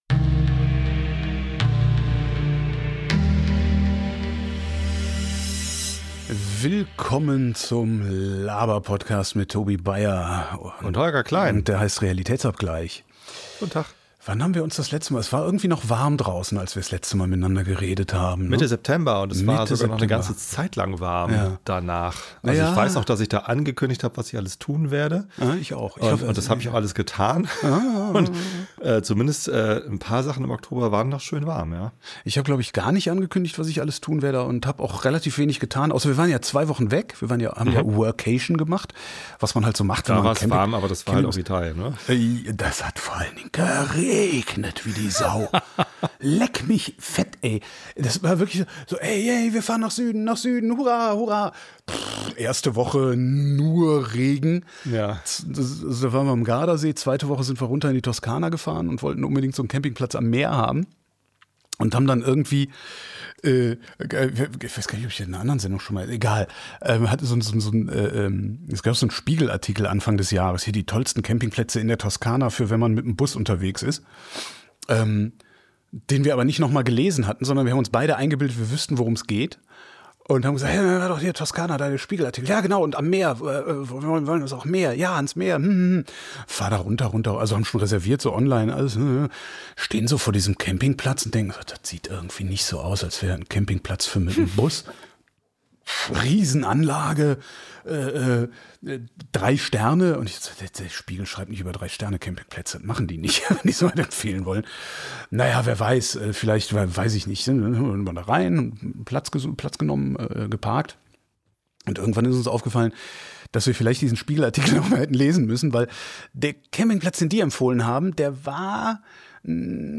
Der Laberpodcast.